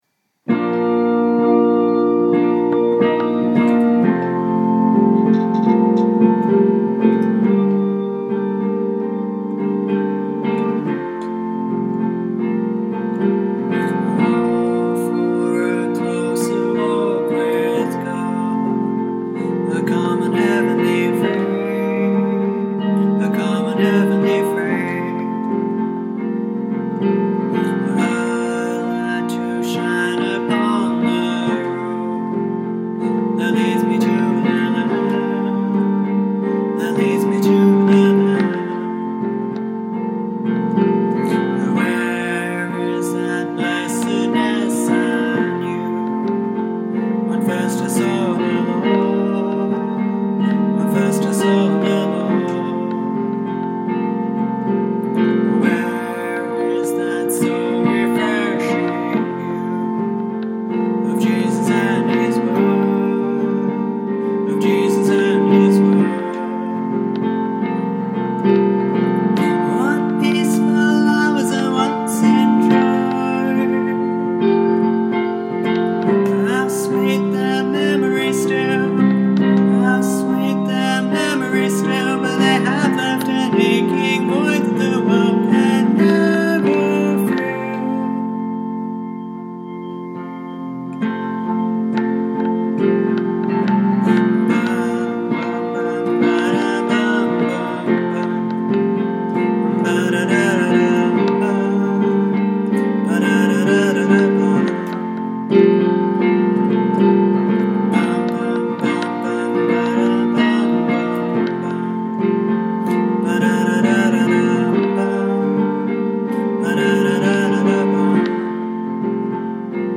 For the past eighteen or so months I’ve been working my way through William Cowper and John Newton’s Olney Hymnbook, setting the less well-known hymns to new tunes. The recordings I have made are not particularly high quality – I don’t have any fancy equipment to record them with.